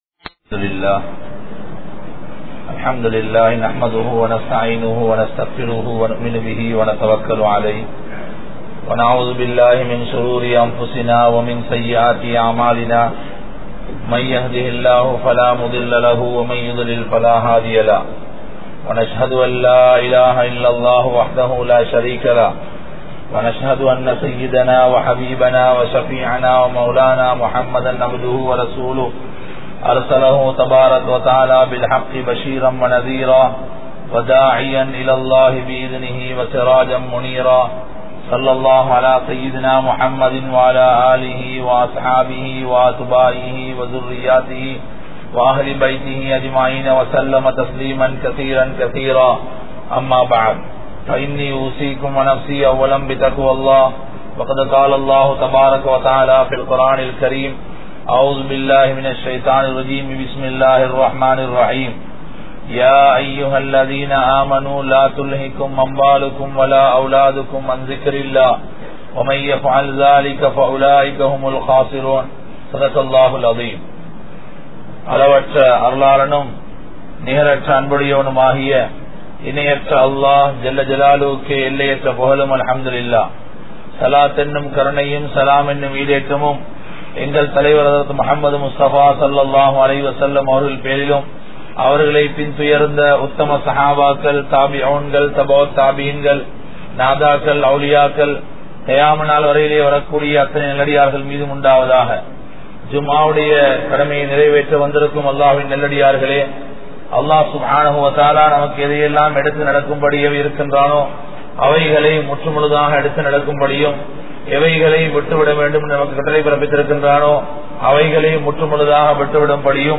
FACEBOOK il Kanavan Manaiviyai Theadufavarhal (FACEBOOKயில் கனவன் மனைவியை தேடுபவர்கள்) | Audio Bayans | All Ceylon Muslim Youth Community | Addalaichenai